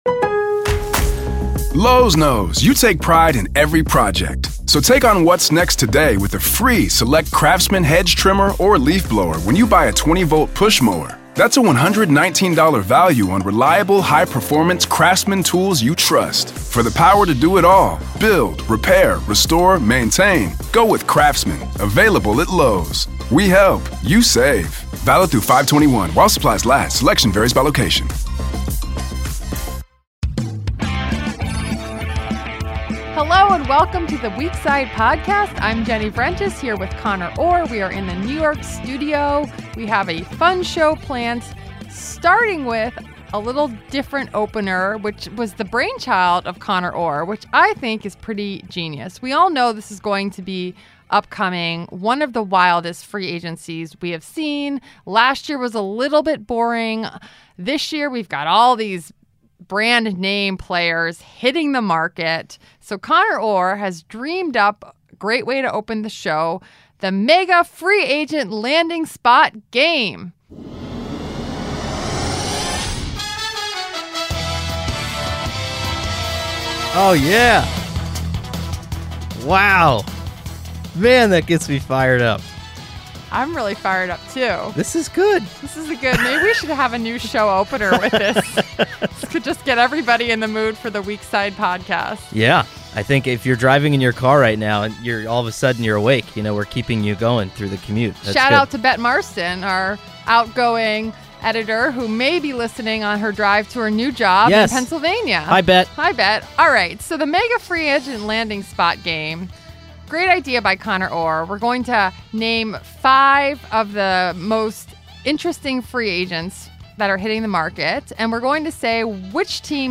We are in the New York studio.